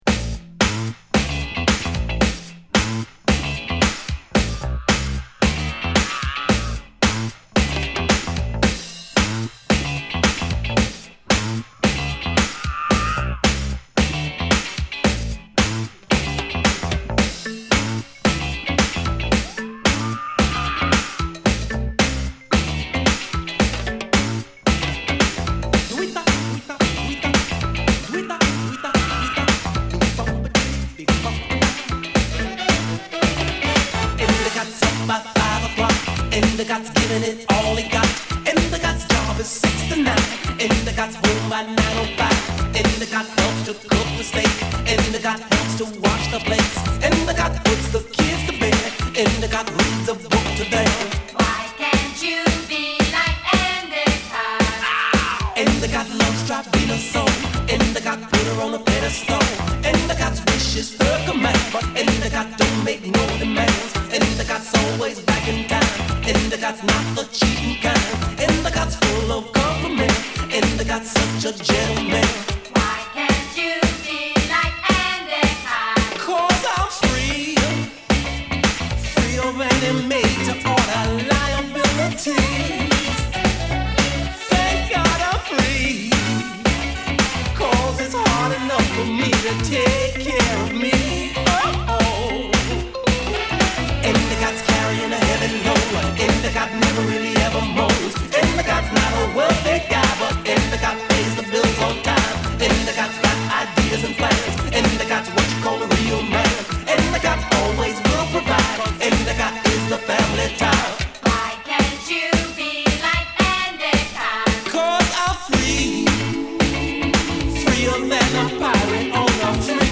軽快なリズムに男女ヴォーカルにホーンやギターが華やかに絡むラテンポップディスコ！